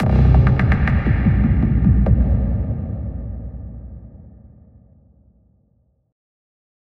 Processed Hits 16.wav